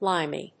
音節lim・y 発音記号・読み方
/lάɪmi(米国英語)/